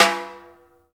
Index of /90_sSampleCDs/Roland - Rhythm Section/KIT_Drum Kits 7/KIT_Loose Kit
SNR RIM - 0O.wav